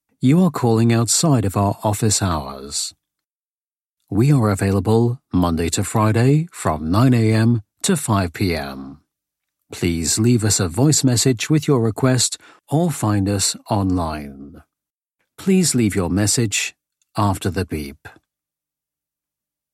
Demos in Audioproduktionen
Telefonansage - On hold message